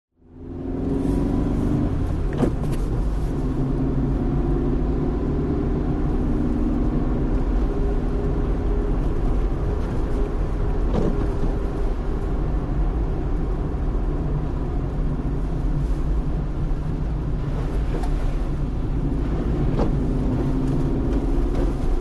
Звуки джипа
Атмосферный звук автомобиля при движении изнутри